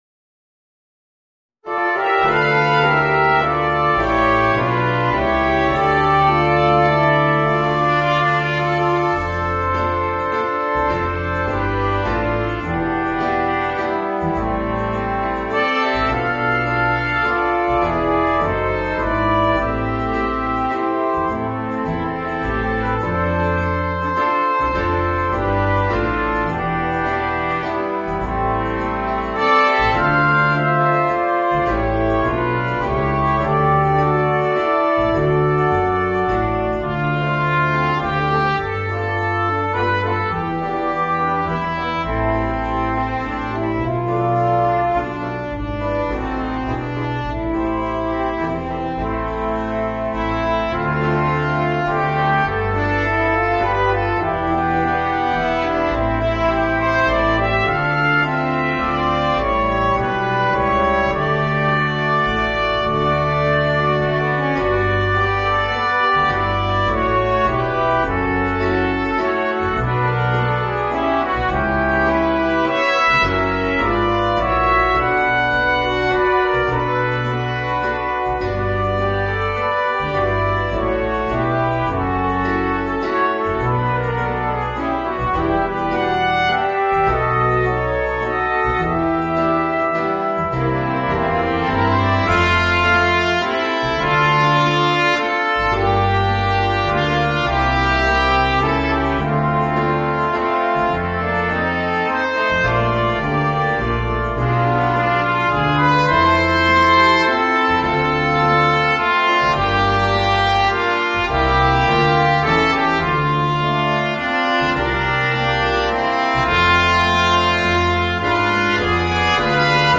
Voicing: 2 Trumpets, Trombone and Euphonium